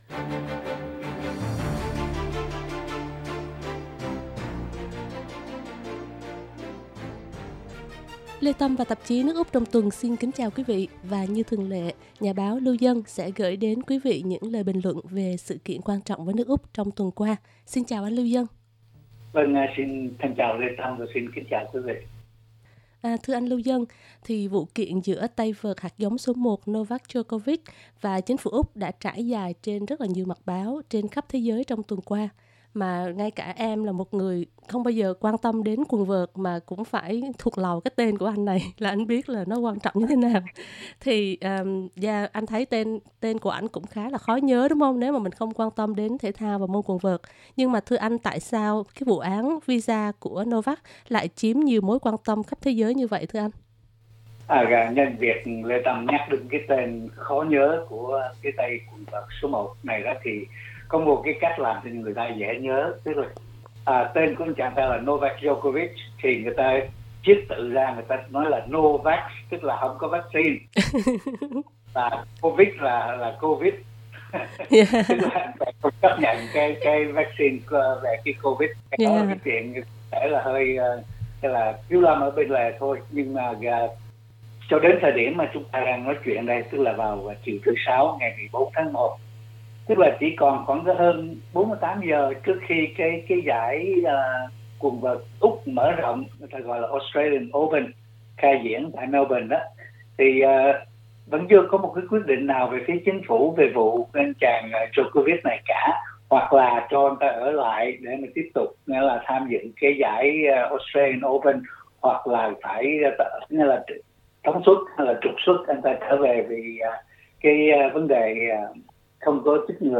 Cuộc phỏng vấn được thực hiện ngay trước thời gian thông báo huỷ visa của Novak Djokovic.